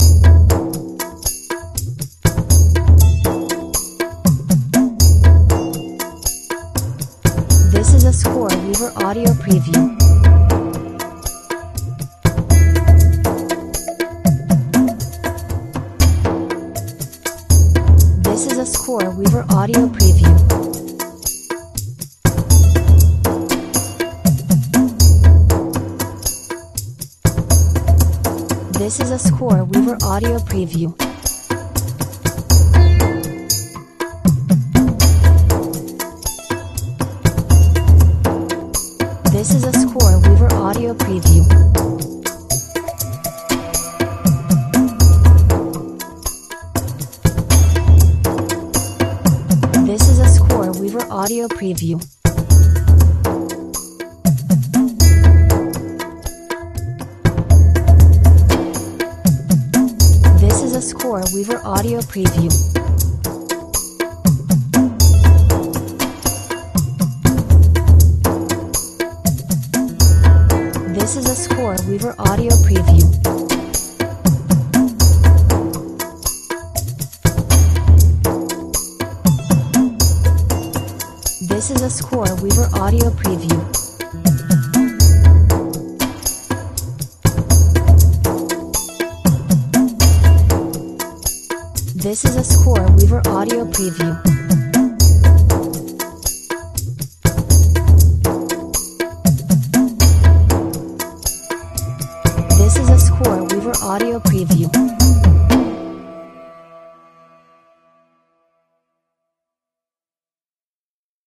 Hypnotic Indian/Pakistani percussive music.